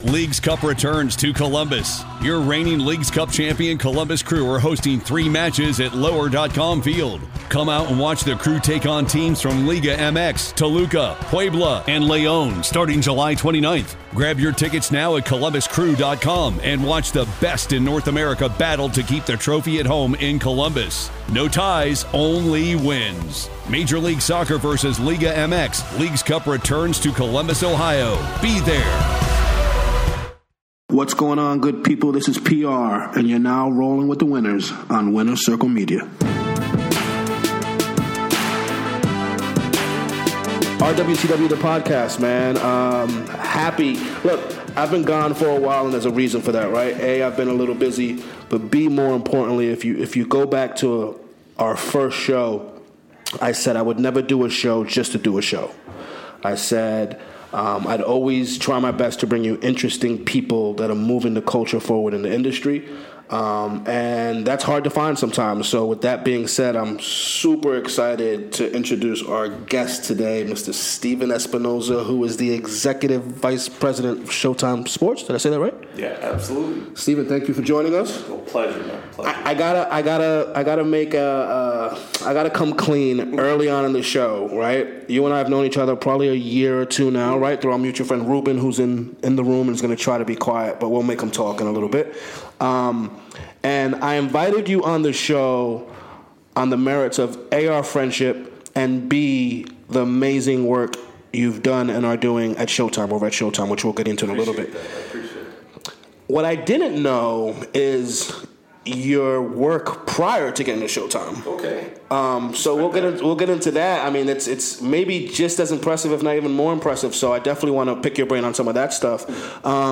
dynamic interview!